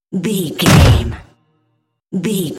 Dramatic hit deep electronic metal
Sound Effects
Atonal
heavy
intense
dark
aggressive